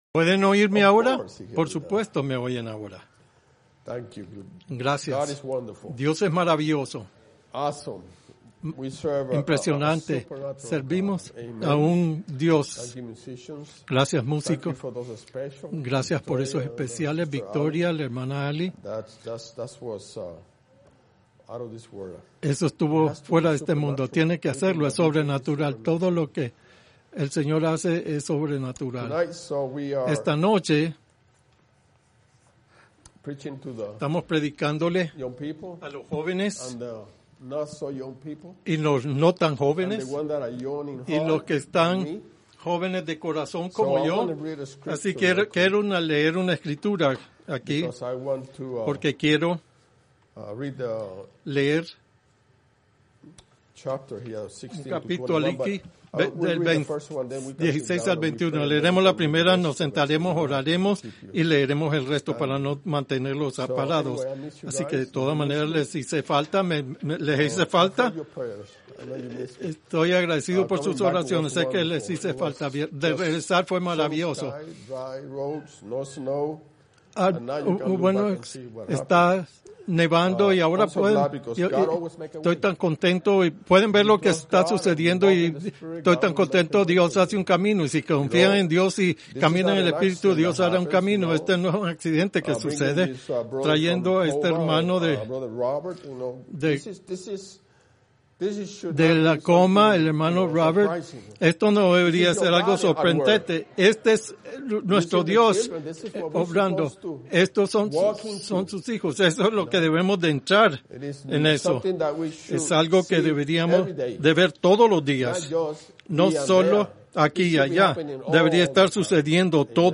Reunión Para Jóvenes